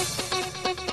guitar nș 145
guitar145.mp3